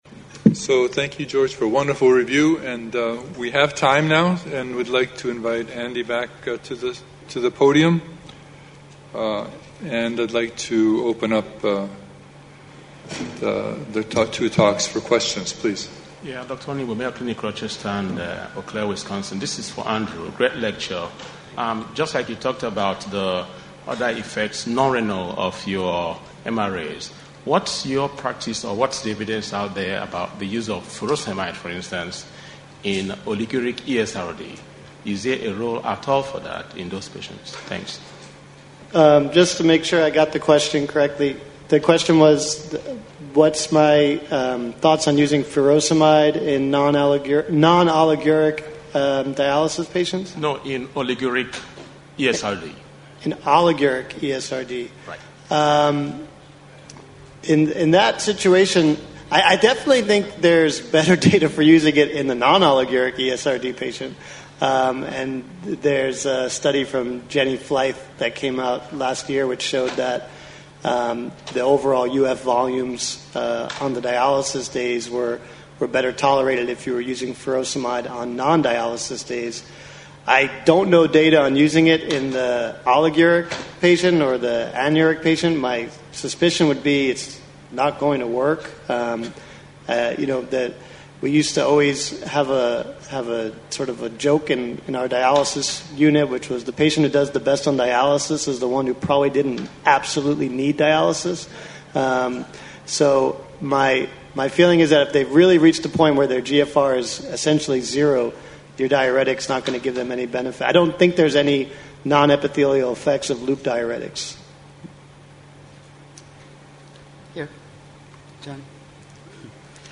Discussions